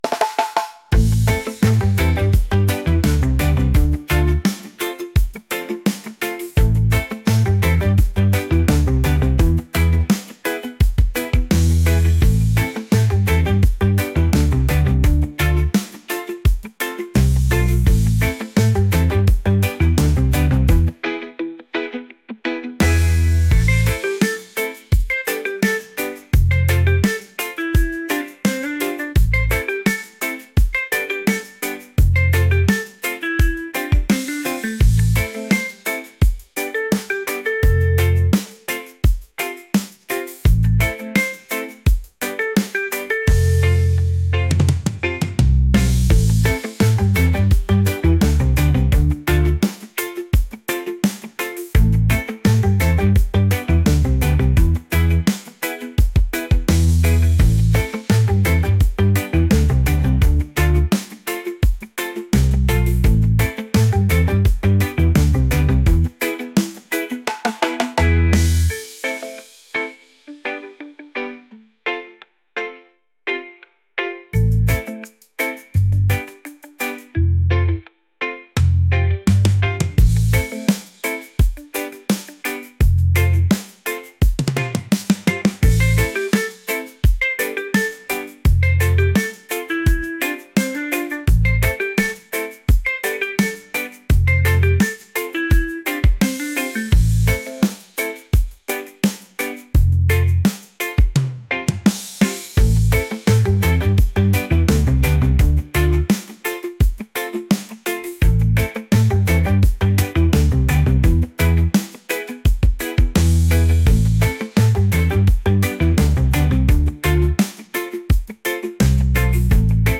catchy | reggae | upbeat